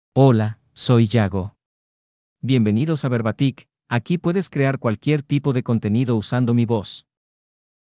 MaleSpanish (Mexico)
Yago — Male Spanish AI voice
Yago is a male AI voice for Spanish (Mexico).
Voice sample
Yago delivers clear pronunciation with authentic Mexico Spanish intonation, making your content sound professionally produced.